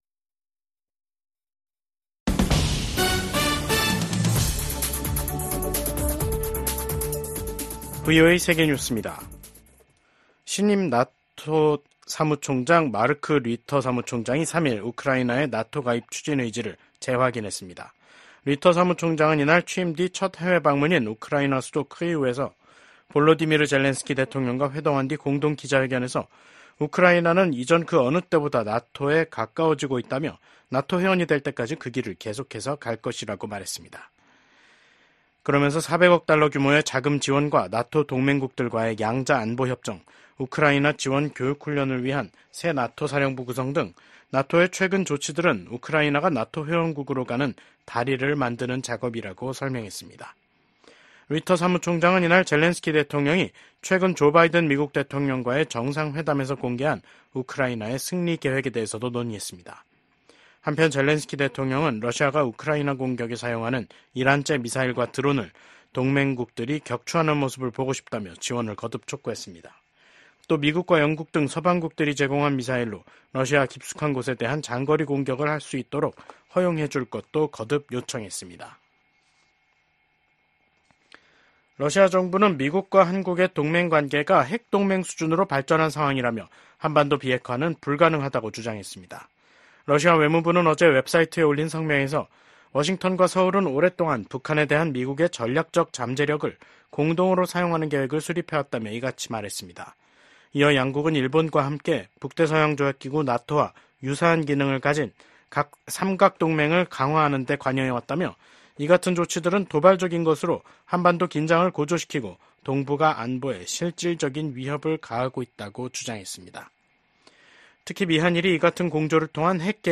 VOA 한국어 간판 뉴스 프로그램 '뉴스 투데이', 2024년 10월 3일 3부 방송입니다. 미국 국무부의 커트 캠벨 부장관은 러시아 군대의 빠른 재건이 중국, 북한, 이란의 지원 덕분이라고 지적했습니다. 조 바이든 미국 대통령이 새로 취임한 이시바 시게루 일본 총리와 처음으로 통화하고 미한일 협력을 강화와 북한 문제를 포함한 국제 정세에 대응하기 위한 공조를 재확인했습니다.